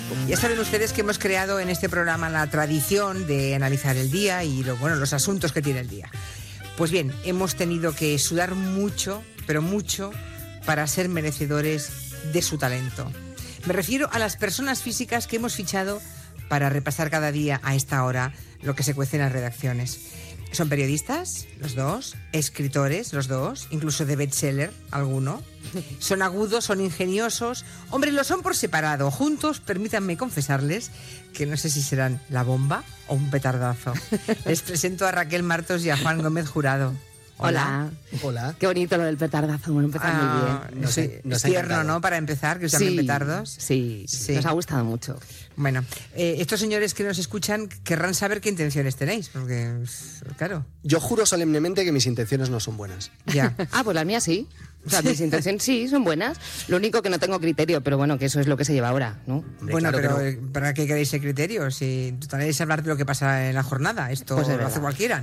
Estrena de la secció "Personas físicas" amb els periodistes Raquel Martos i Juan Gómez Jurado.
Entreteniment